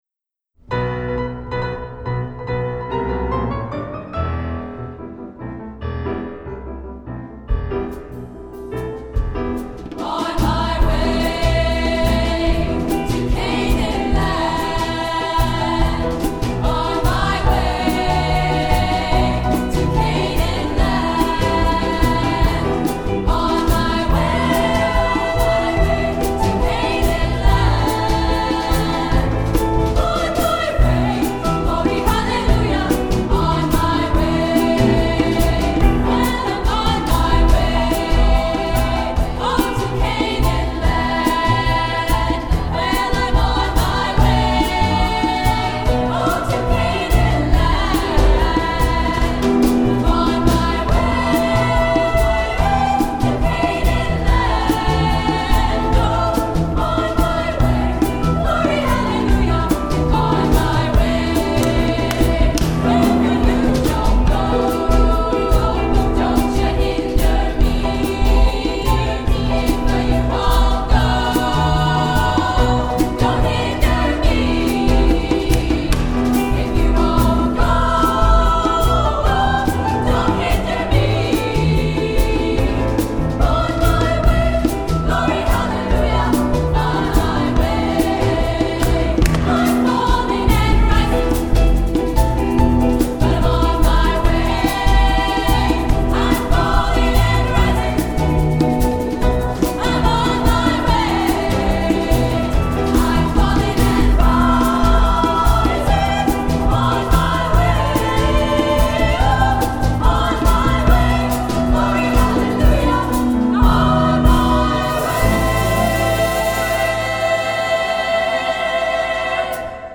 Accompaniment:      Piano
Music Category:      Choral
An accessible and dynamic arrangement.